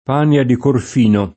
Pania [p#nLa; non -n&a] top. f. (Tosc.) — altro nome, più spesso nel pl. Panie [p#nLe], delle Alpi Apuane; e nome di singole cime, come la Pania della Croce [p#nLa della kre], la Pania di Corfino [